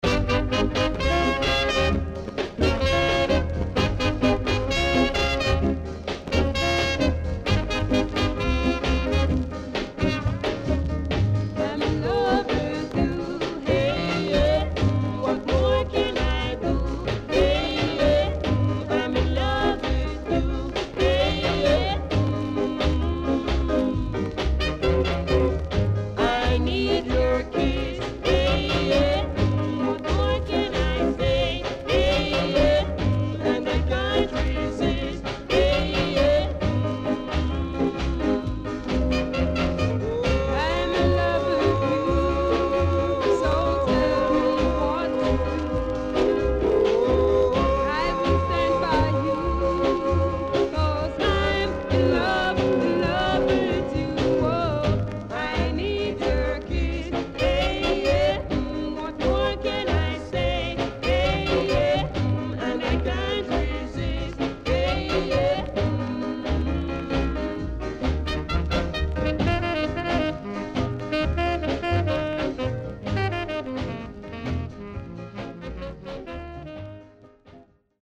HOME > SKA / ROCKSTEADY  >  EARLY 60’s  >  BALLAD
SIDE A:全体的にチリノイズが入ります。